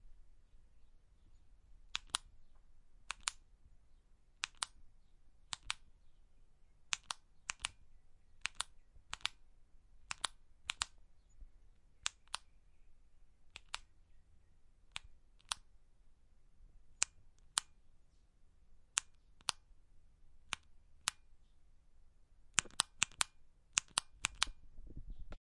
打开天窗说亮话 " 闪光灯的开启和关闭
描述：记录在TASCAM ZOOM H4n上，XY胶囊设置为90°。关闭透视单声道录音。 手电筒开启和关闭。
标签： 点击通 开关 点击断 火炬 按钮 指示灯 点击 OWI 手电筒